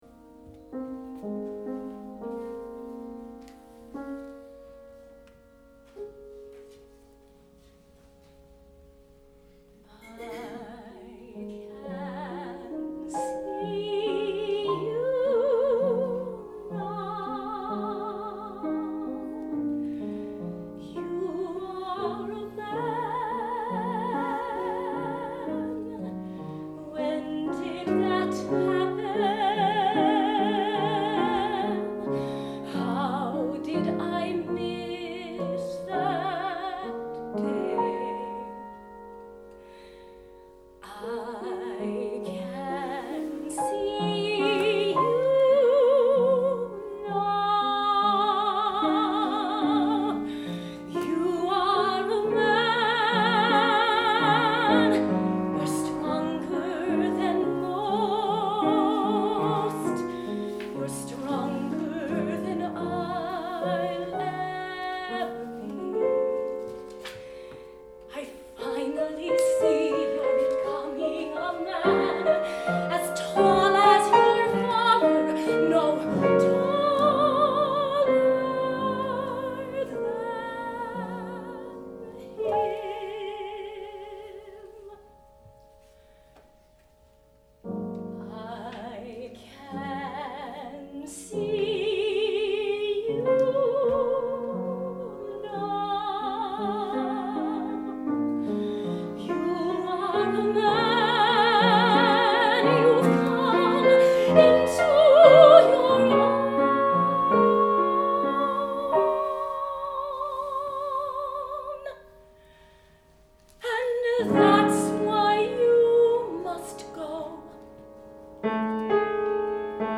counterfugue, 4 voices